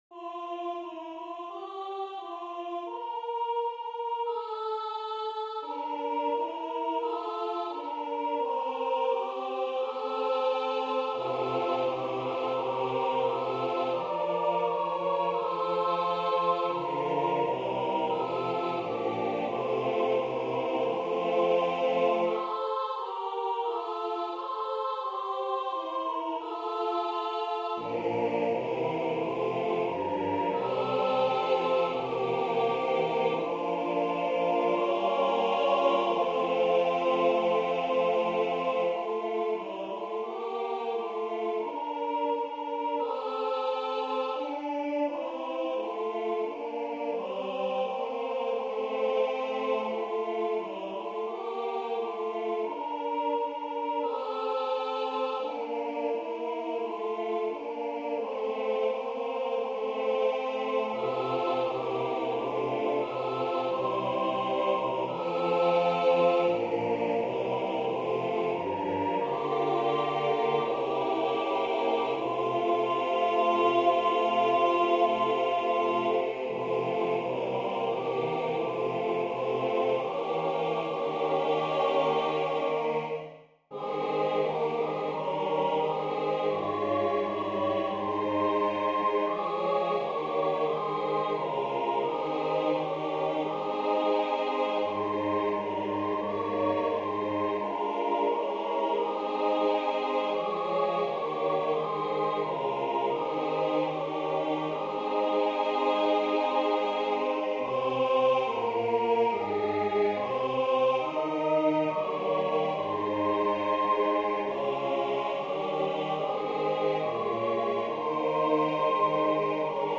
Voicing/Instrumentation: SATB We also have other 43 arrangements of " For the Beauty of the Earth ".